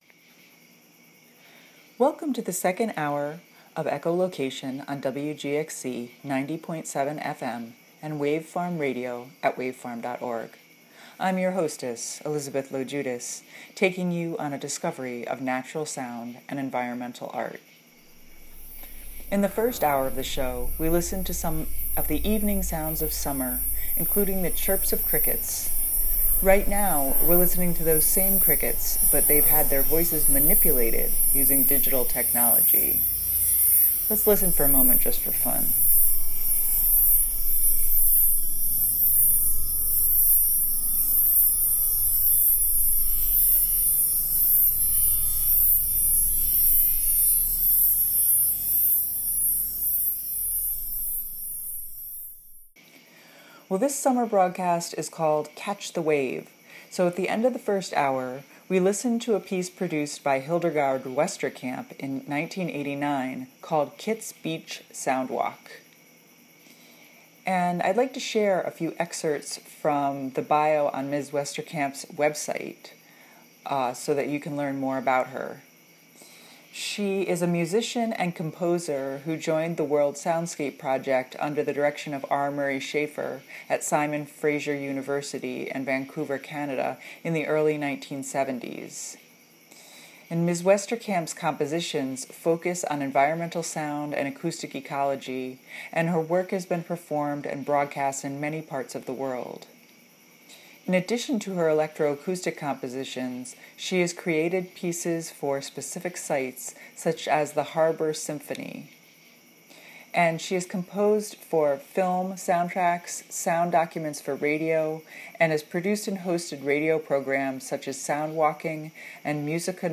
The show dwells at the junction between art, science and natural sound, and features works by artists working in the fields of soundscape studies, deep listening and acoustic ecology.
Beat the heat with the summer broadcast of Echolocation.